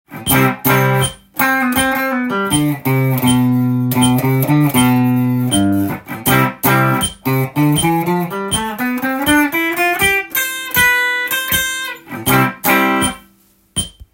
Cだけでギターソロ
Cコードでも２小節毎にコードを弾いて
その後ギターソロを適当に弾いていきます。
Cメジャースケールになります。